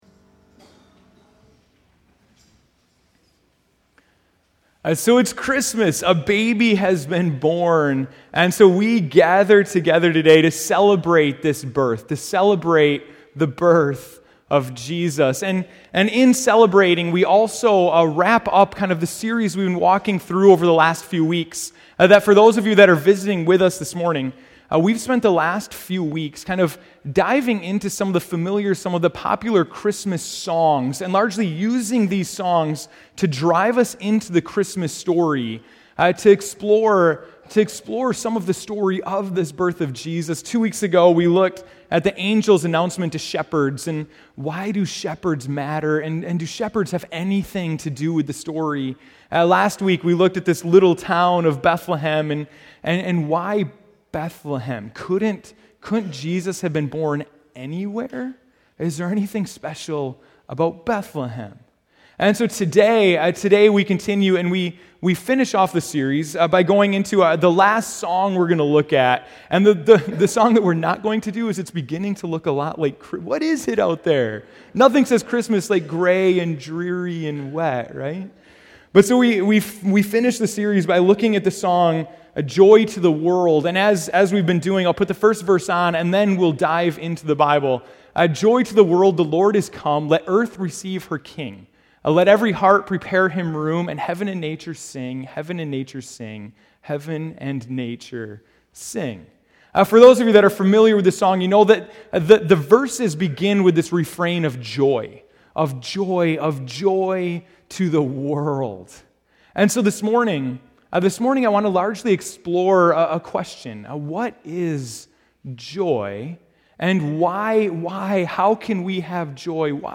December 25, 2014 (Morning Worship)